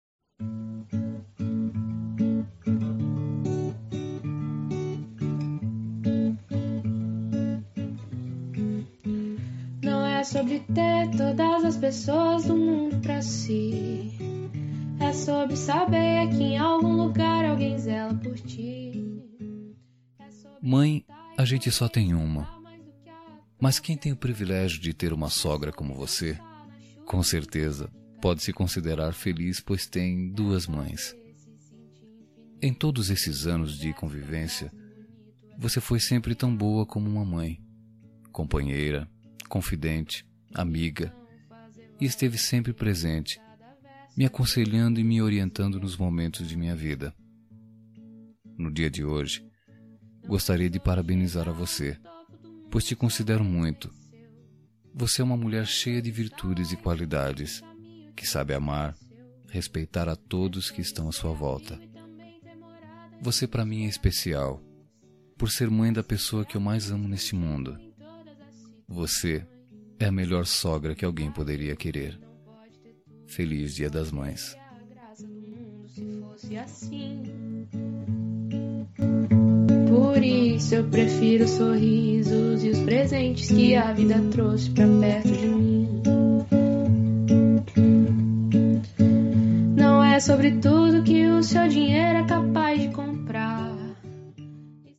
Dia das Mães – Para Madrasta – Voz Masculina – Cód: 6709